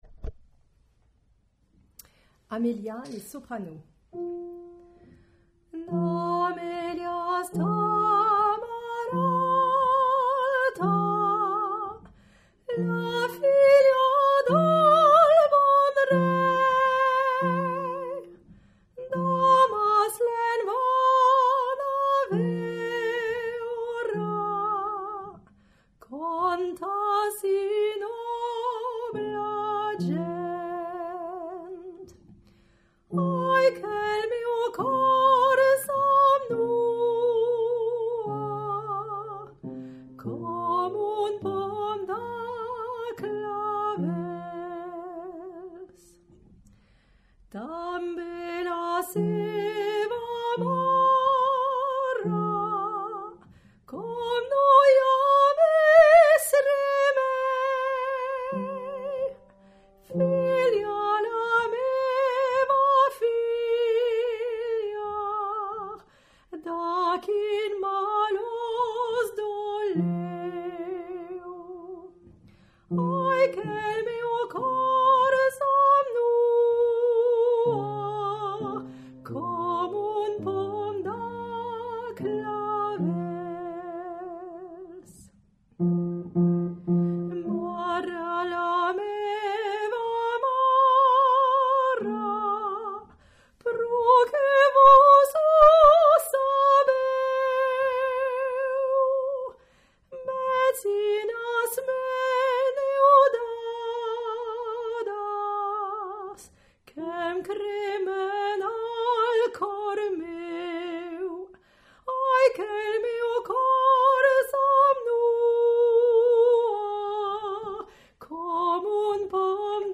Voici une chanson catalane très douce et mélodique.
amelia_Soprano.mp3